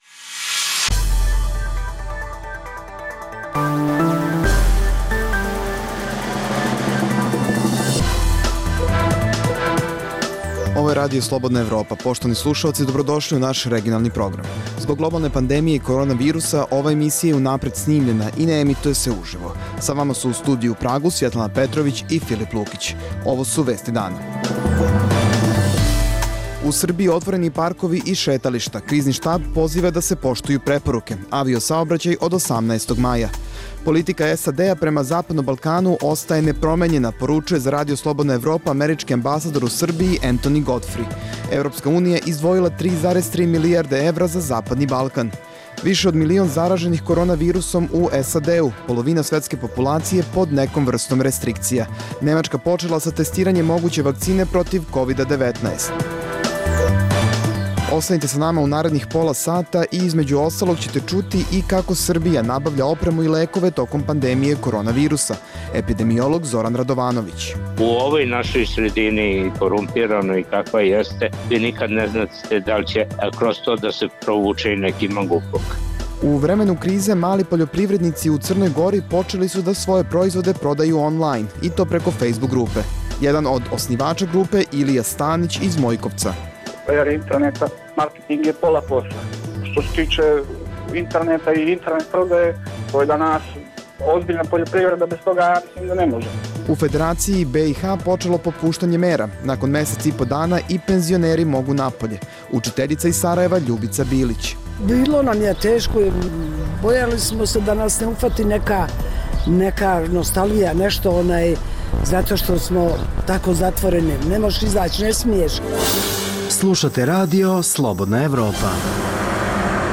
Zbog globalne pandemije korona virusa, ova emisija je unapred snimljena i ne emituje se uživo. U Srbiji otvoreni parkovi i šetališta.